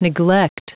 Prononciation du mot neglect en anglais (fichier audio)
Prononciation du mot : neglect